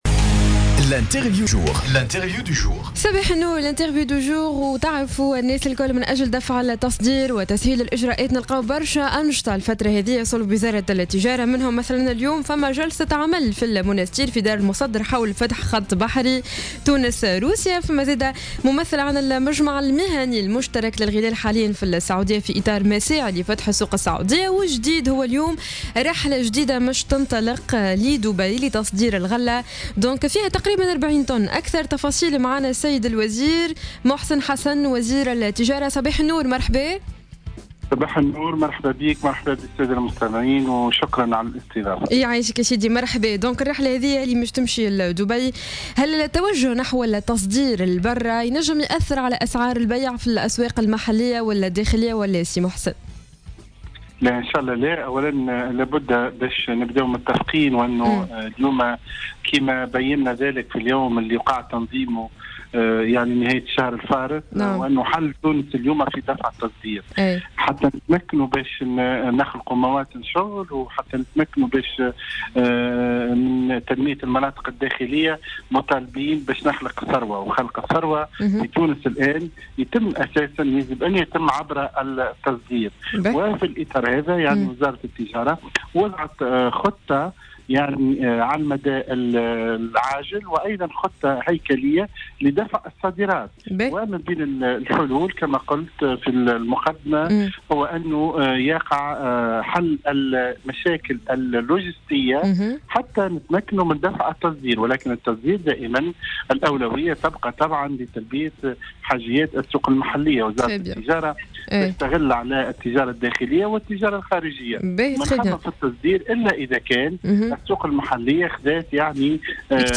وقال محسن حسن في تصريح اليوم ل"الجوهرة أف أم" إن التصدير يعتبر الحل الأمثل للنهوض بالاقتصاد الوطني، مشيرا إلى أن الوزارة وضعت خطة وطنية لدفع الصادرات التونسية وتبسيط الإجراءات الديوانية وكذلك تفادي الإشكاليات الحاصلة على مستوى نقل البضائع.